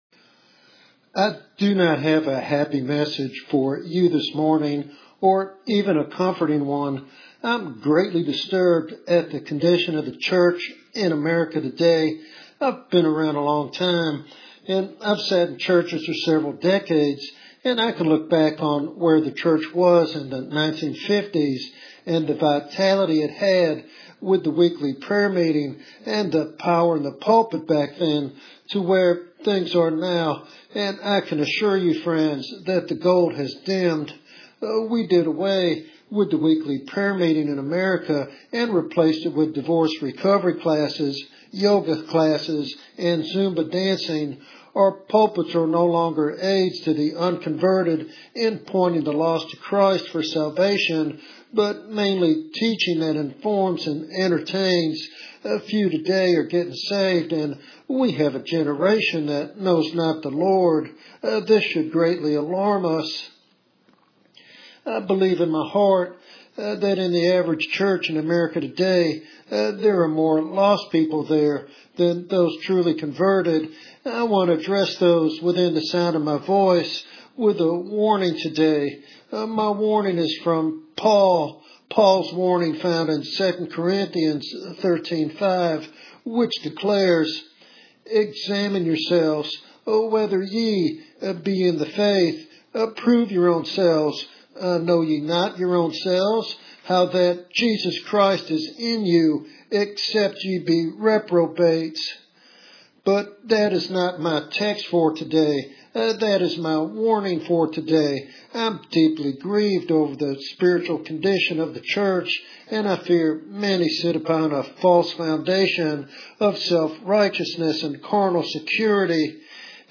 This message serves as a solemn warning and an urgent invitation to authentic faith.